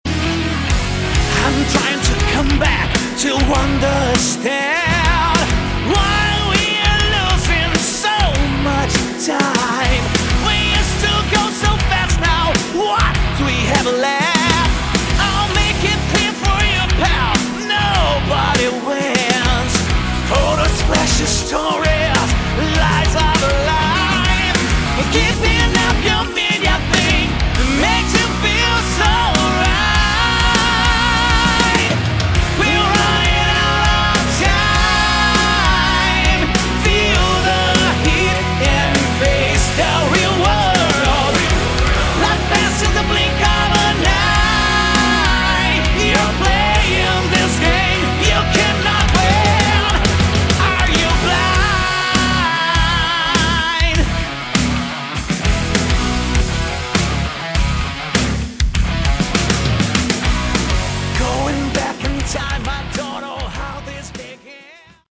Category: Melodic Hard Rock
Guitars
Drums
Bass
Backing vocals